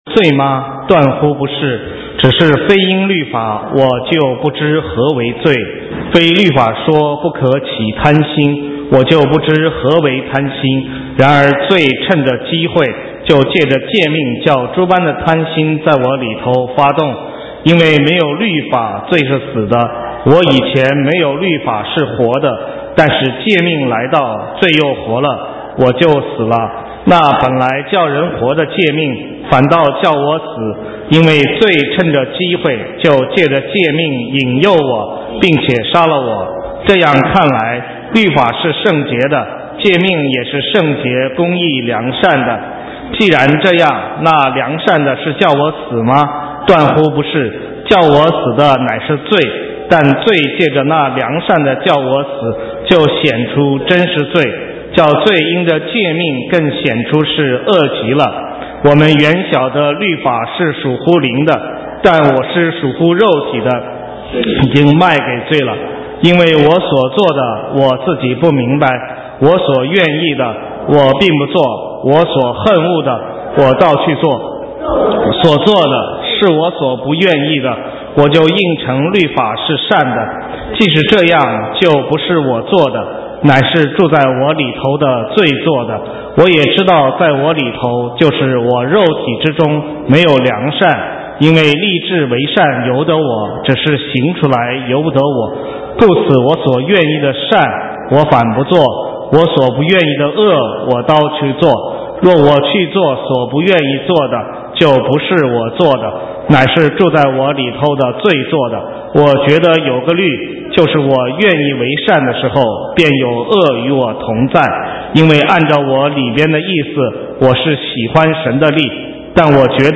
神州宣教--讲道录音 浏览：得胜者的呼召与渴望 (2012-04-01)